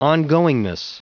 Prononciation du mot ongoingness en anglais (fichier audio)
Prononciation du mot : ongoingness